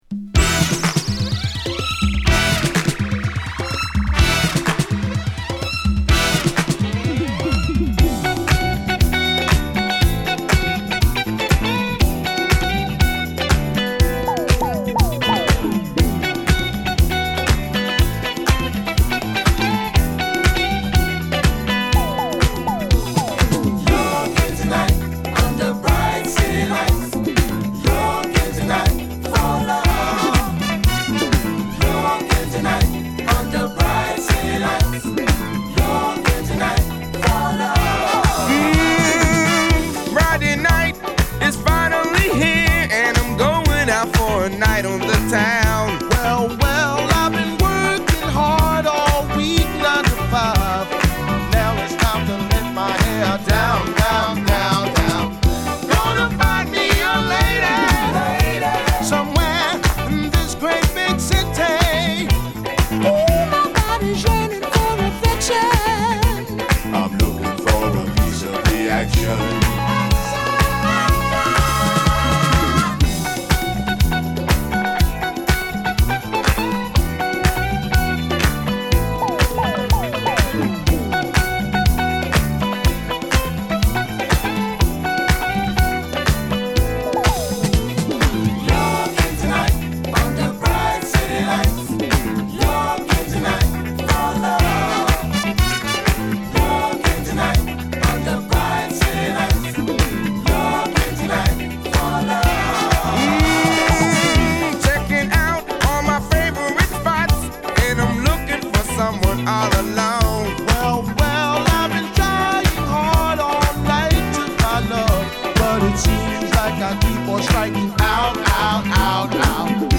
気持ちよすぎる爽快フィリーダンサー大名曲！！